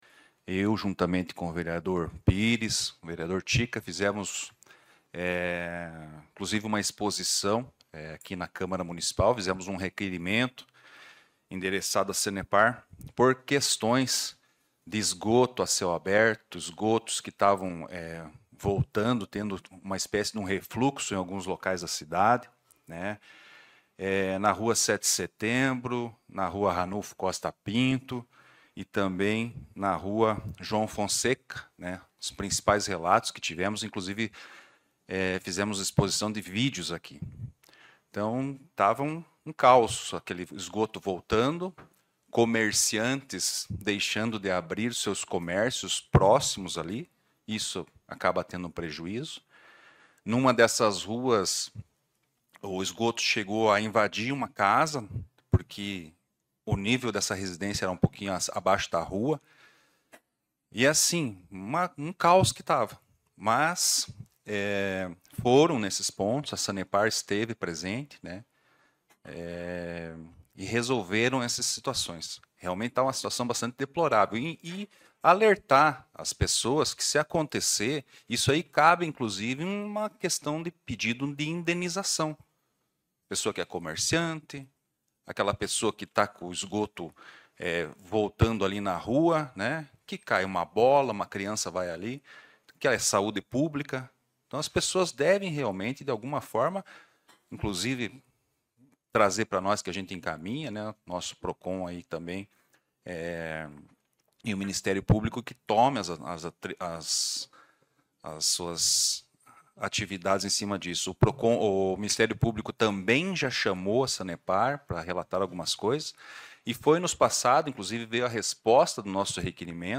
Ricardo Sass no seu Pronunciamento